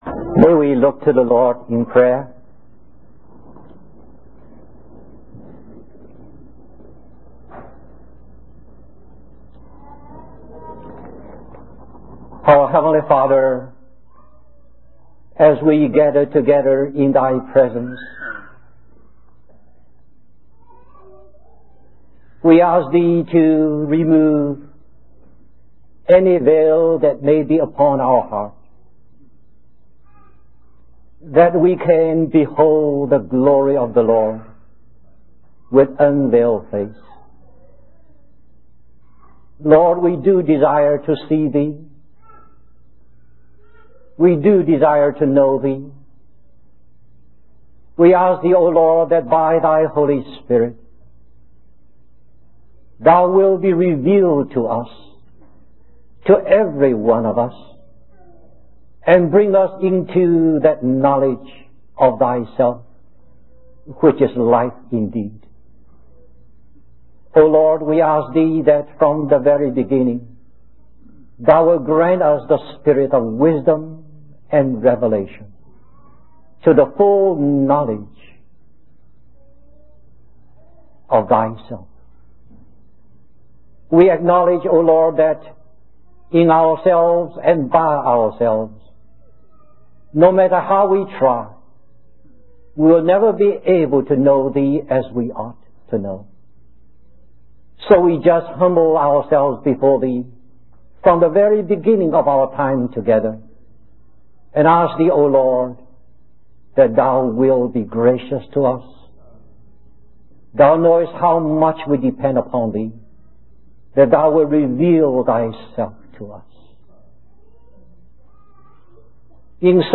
In this sermon, the preacher emphasizes that God is the Creator of all things and that all things were created by His will.